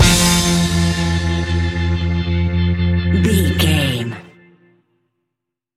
Ionian/Major
60s
fun
energetic
uplifting
acoustic guitars
drums
bass guitar
electric guitar
piano
organ